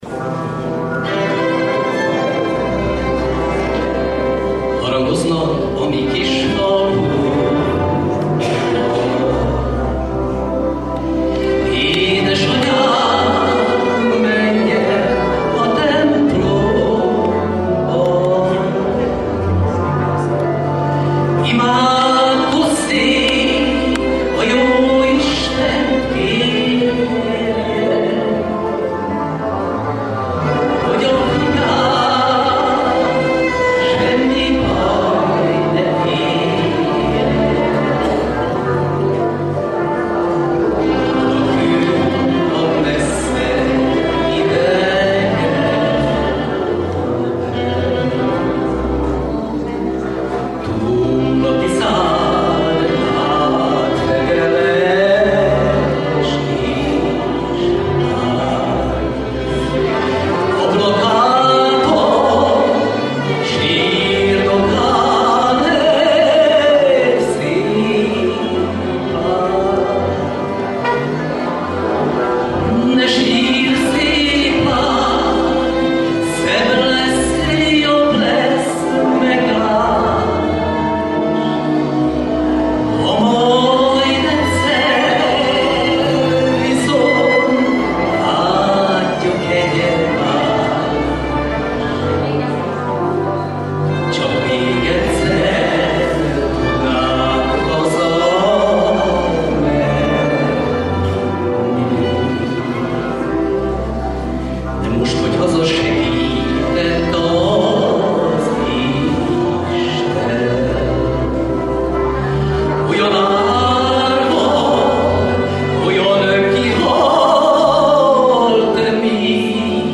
KategóriaŐszirózsa - a magyarnóta-énekesek versenye